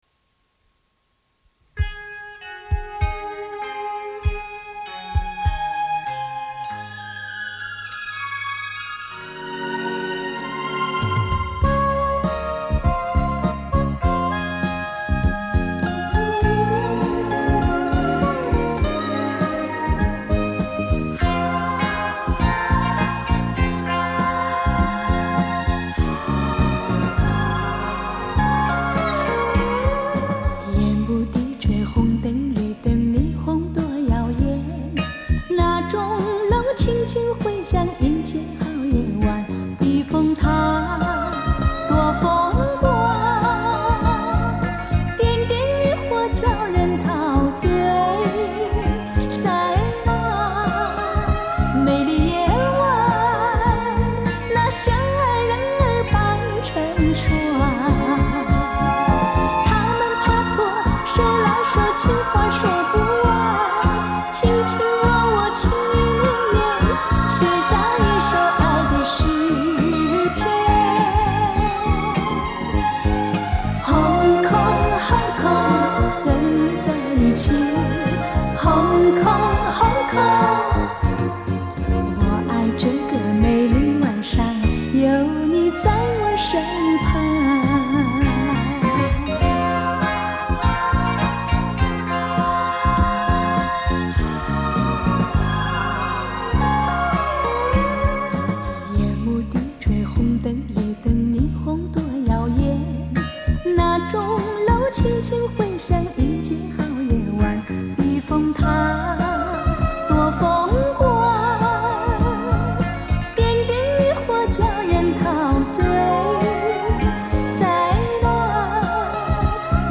录制的音质不错